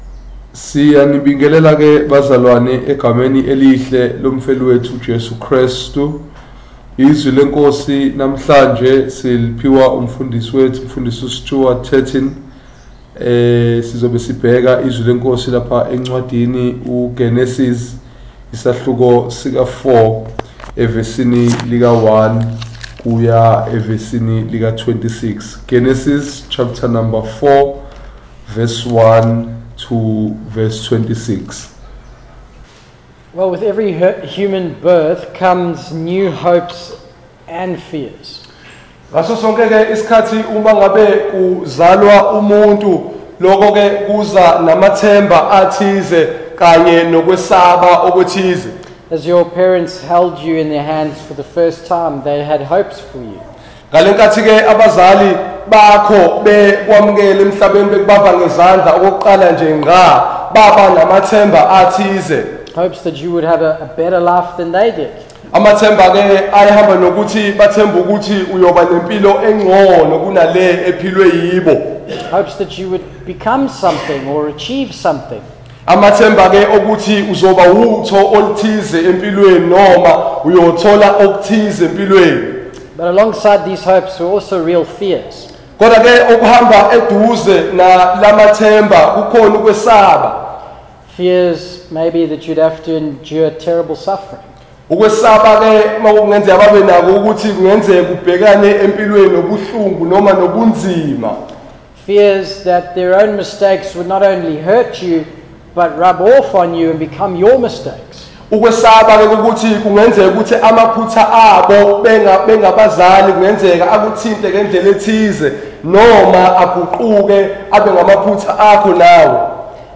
Zulu Sermon